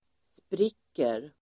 Uttal: [spr'ik:er]